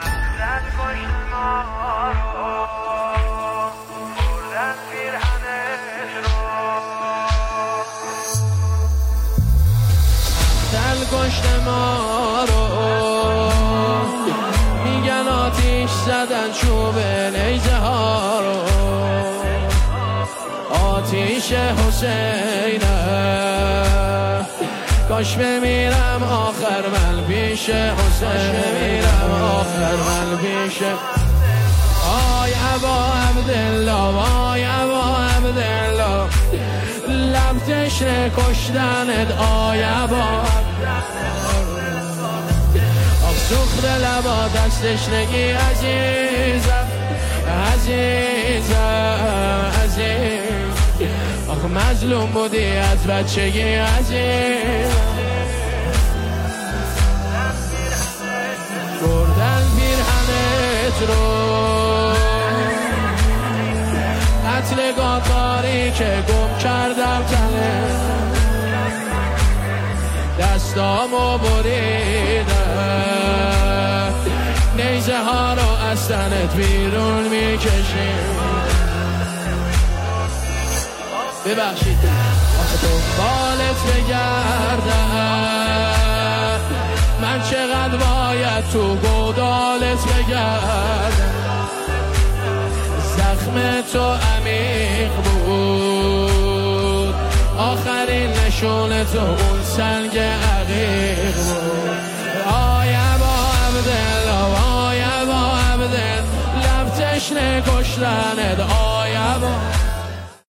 نماهنگ دلنشین
مداحی مذهبی